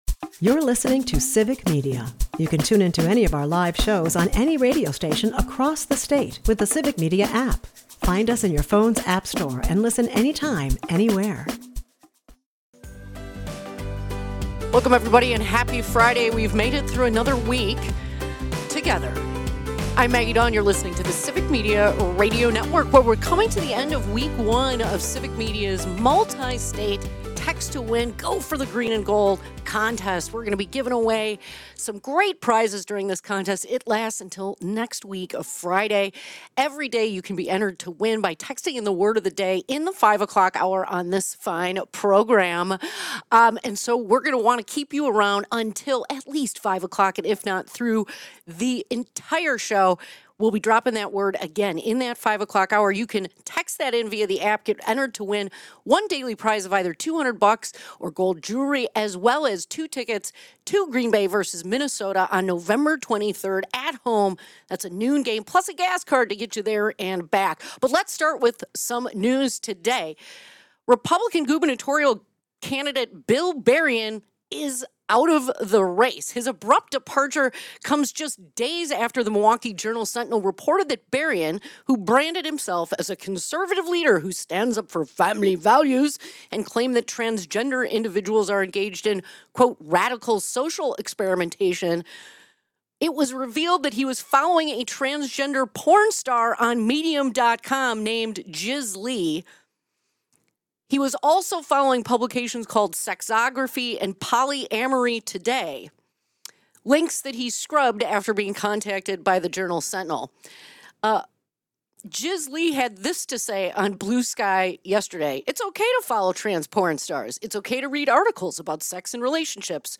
Dive into a riveting discussion on the enduring mystery of the Edmund Fitzgerald's sinking and its symbolic intertwining with America's economic upheaval in the 1970s.